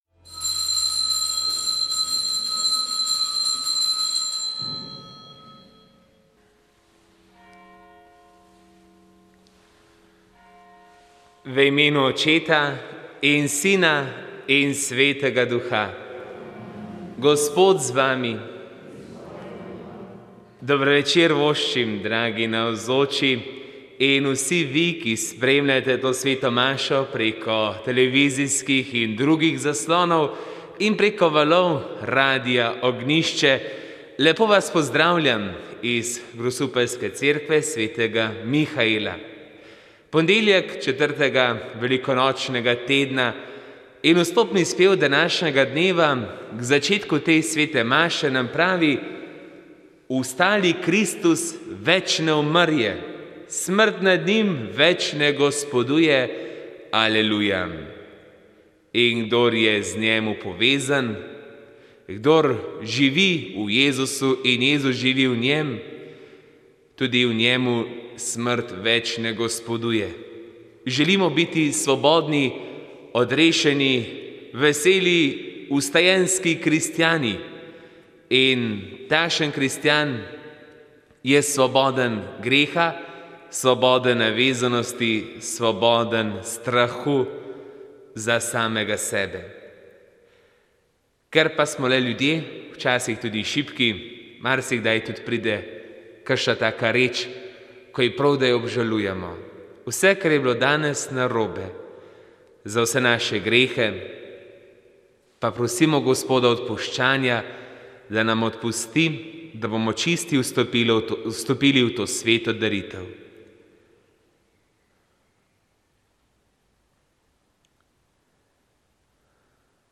Sveta maša
Sv. maša iz cerkve sv. Mihaela v Grosuplju 26. 4.